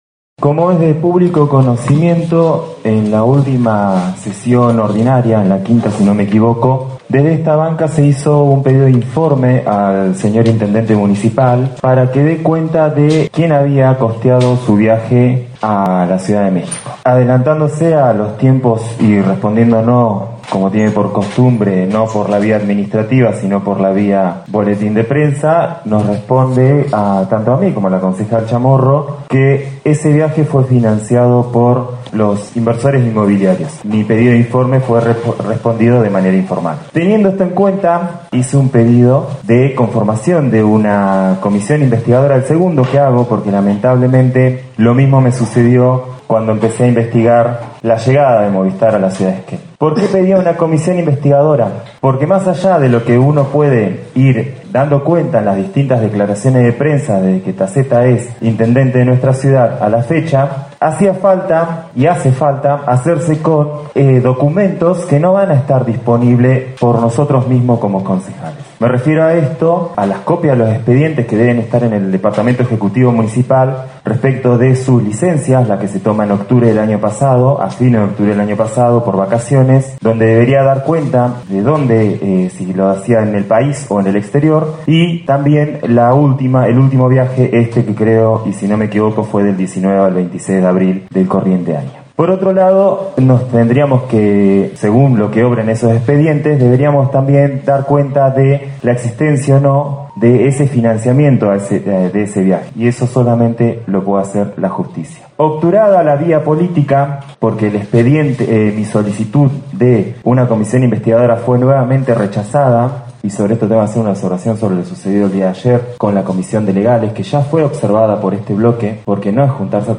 Durante la sexta sesión ordinaria del Concejo Deliberante, dos concejales dieron a conocer que denunciaron al Intendente Matías Taccetta por el viaje a México.
En principio fue el Concejal Martín Escalona, quien informó durante la hora de preferencia, que denunció a Taccetta por el presunto delito de cohecho.